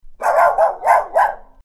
Dog Bark Type 01 Efecto de Sonido Descargar
Dog Bark Type 01 Botón de Sonido